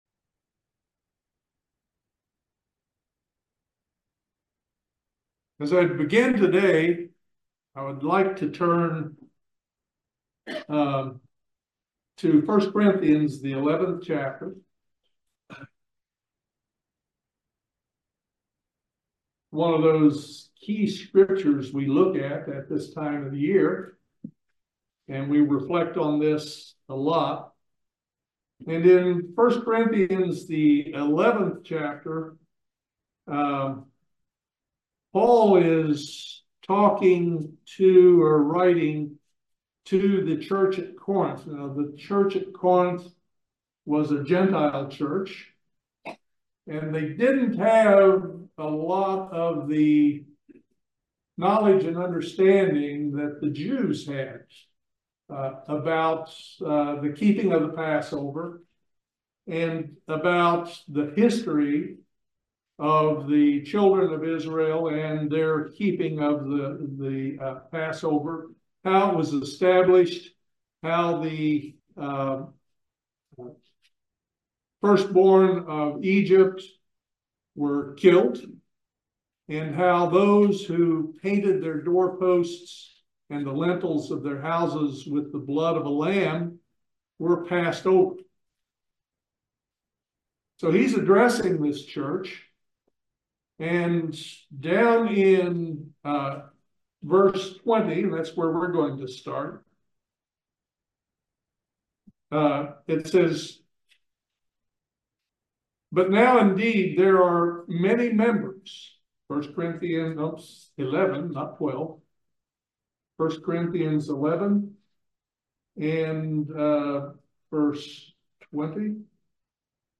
This is an excellent video sermon on discerning the Lord's body leading up to the Passover.